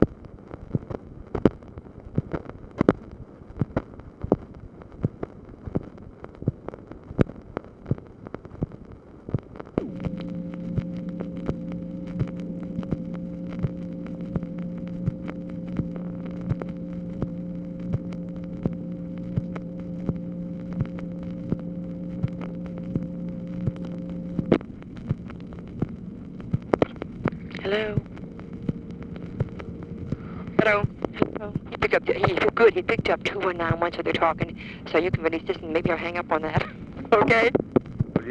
Conversation with TELEPHONE OPERATOR
Secret White House Tapes